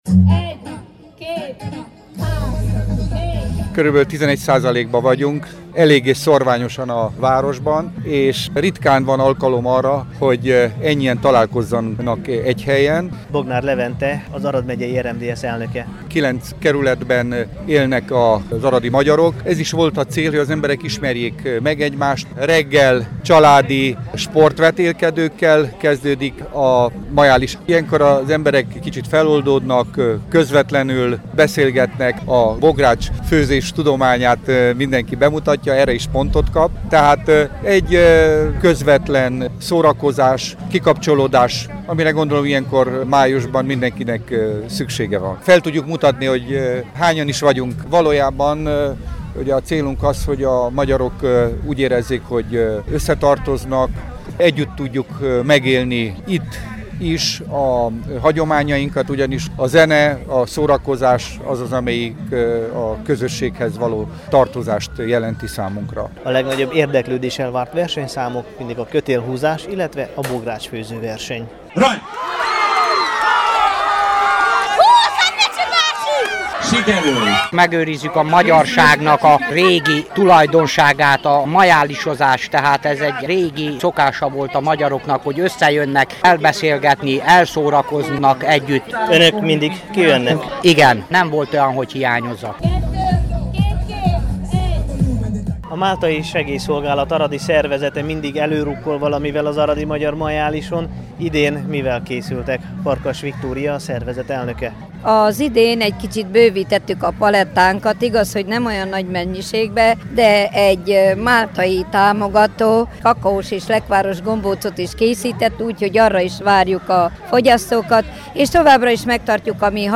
Aradi_Magyar_Majalis_2014.mp3